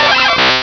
-Replaced the Gen. 1 to 3 cries with BW2 rips.
kabutops.aif